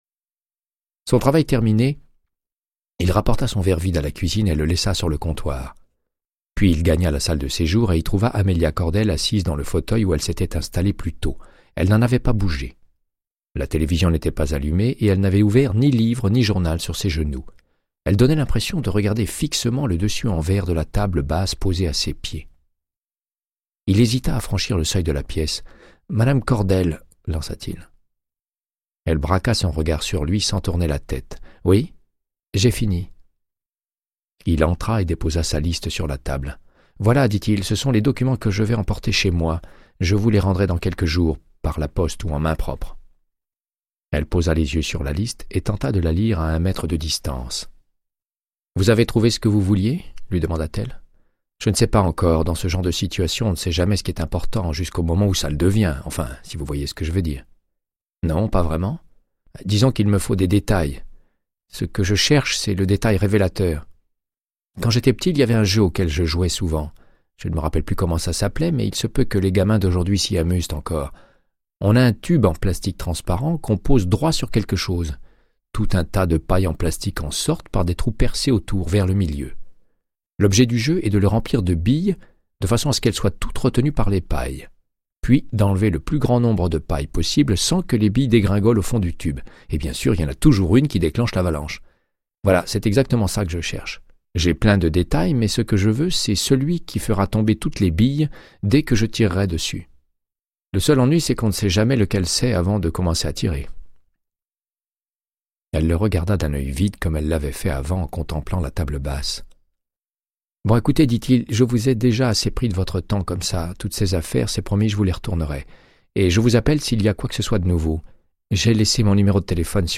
Audiobook = Créance de sang, de Michael Connellly - 100